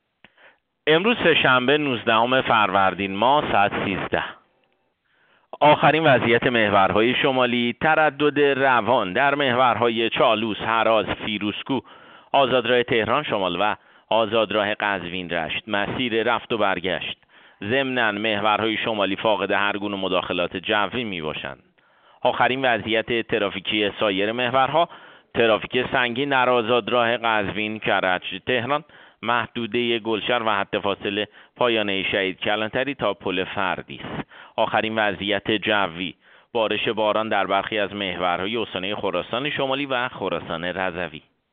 گزارش رادیو اینترنتی از آخرین وضعیت ترافیکی جاده‌ها ساعت ۱۳ نوزدهم فروردین؛